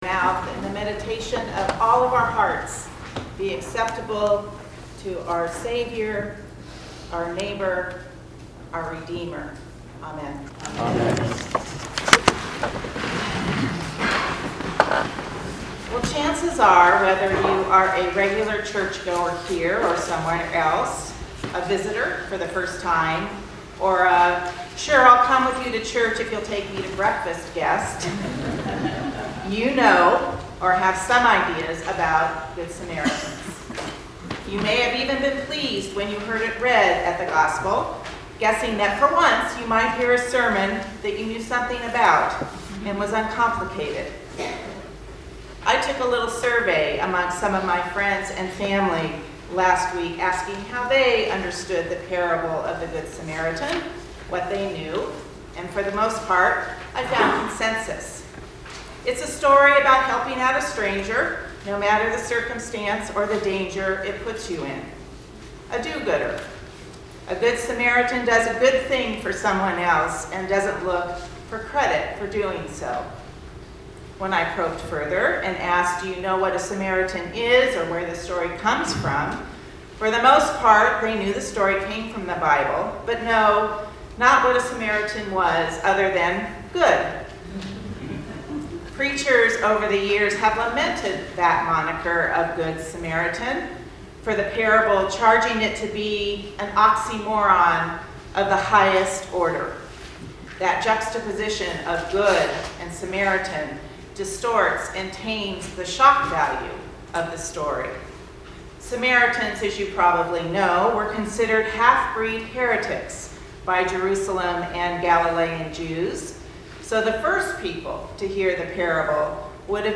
Today’s Sermon